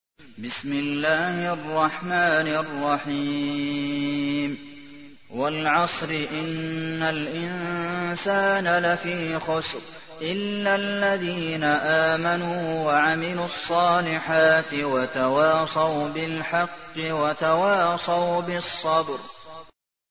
تحميل : 103. سورة العصر / القارئ عبد المحسن قاسم / القرآن الكريم / موقع يا حسين